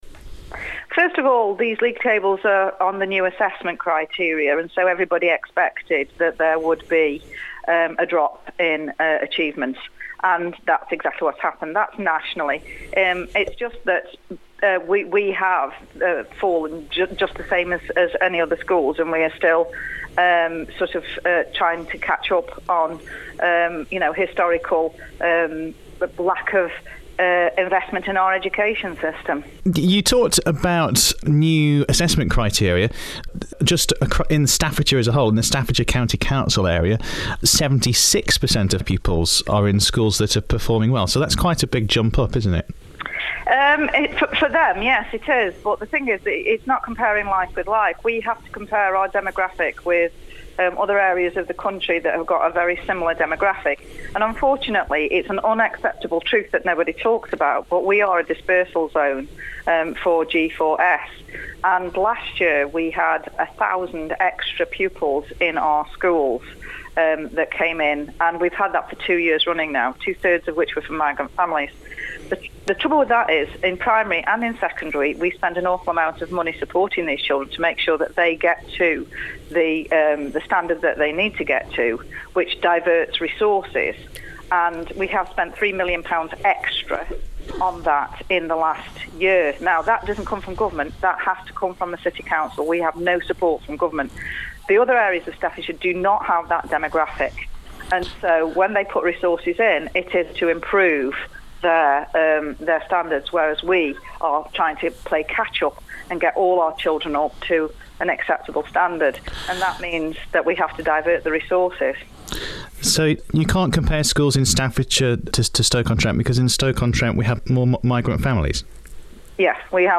Cllr Janine Bridges is cabinet member for education at Stoke-on-Trent City Council says city schools face certain pressures but are making progress. First broadcast on Cross Rhythms City Radio on 6 December 2016.